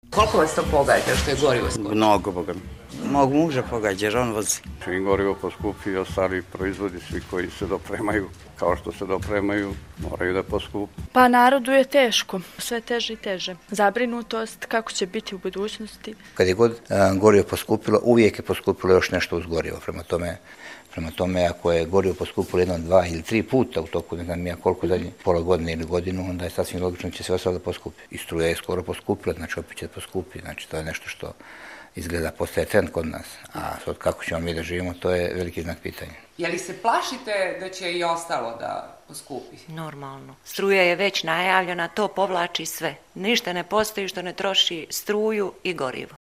Građani o poskupljenjima